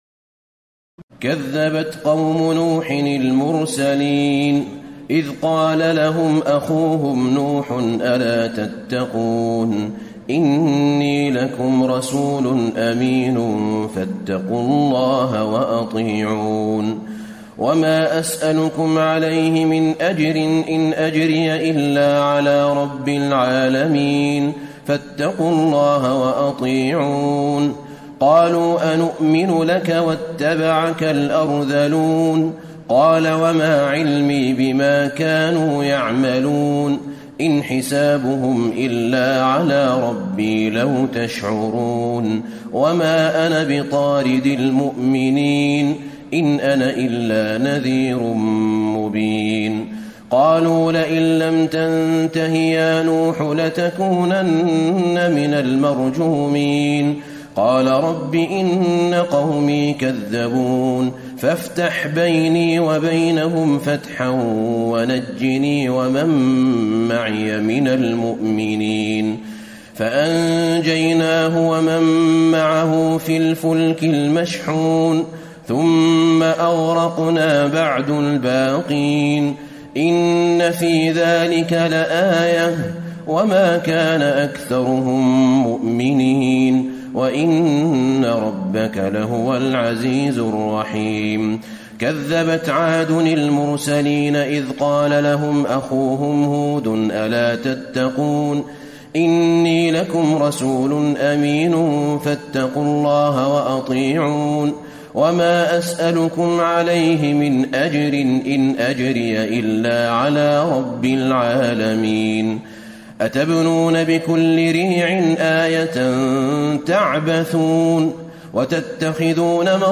تراويح الليلة الثامنة عشر رمضان 1434هـ من سورتي الشعراء (105-227) والنمل (1-53) Taraweeh 18 st night Ramadan 1434H from Surah Ash-Shu'araa and An-Naml > تراويح الحرم النبوي عام 1434 🕌 > التراويح - تلاوات الحرمين